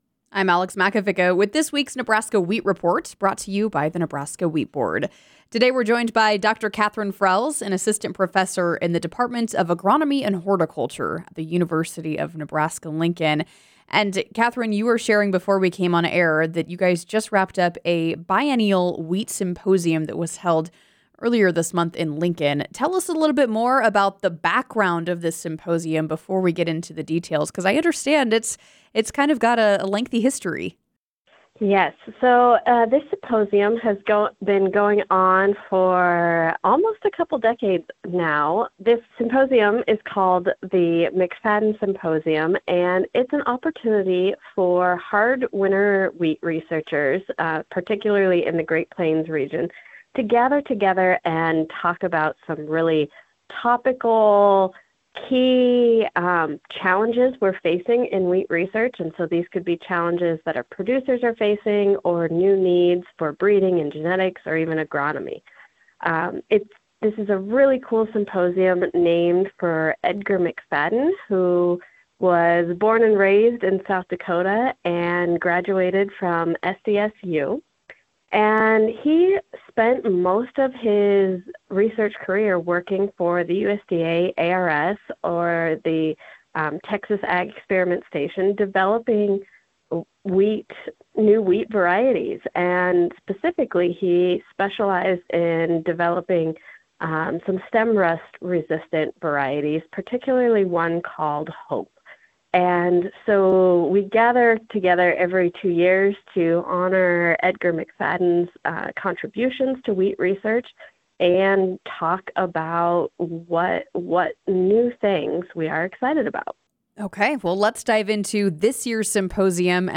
The Wheat Report are interviews conducted with farmers and wheat industry representatives regarding current events and issues pertaining to the Nebraska Wheat Board.